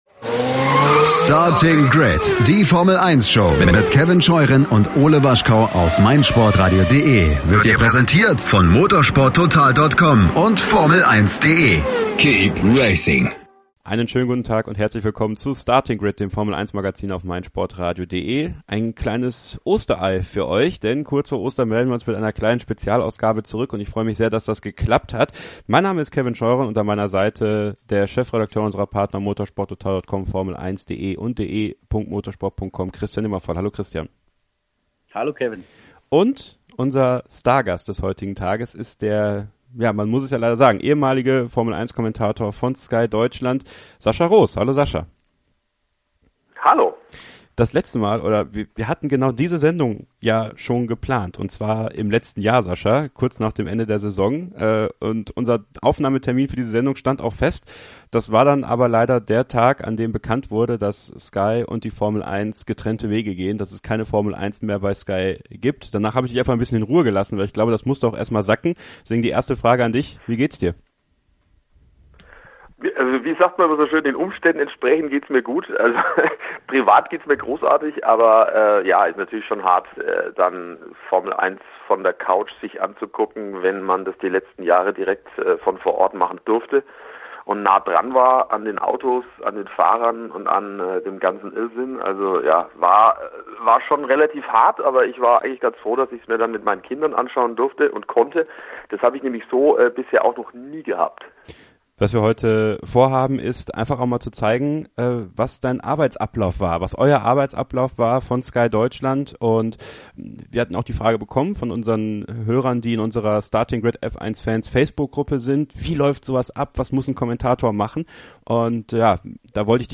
Es ergab sich ein buntes, sehr kurzweiliges und spannendes Gespräch.